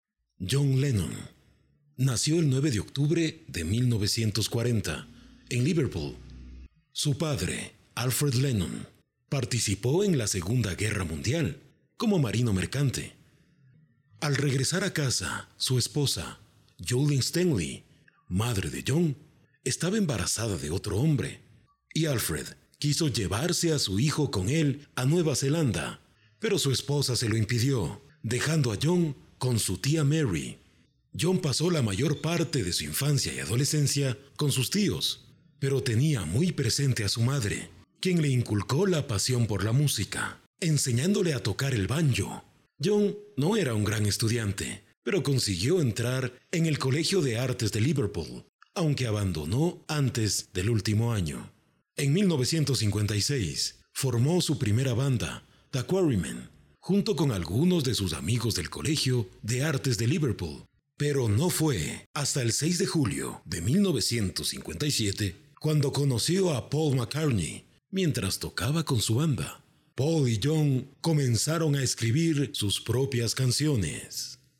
Barítono bajo, con acento neutro, que puede alcanzar figuras detalladas en el romance o en la narración.
Sprechprobe: Sonstiges (Muttersprache):